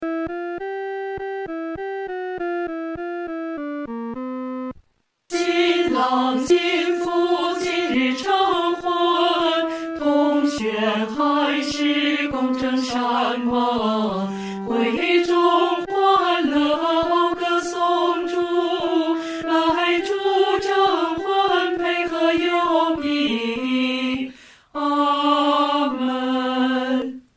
女高 下载
本首圣诗由网上圣诗班录制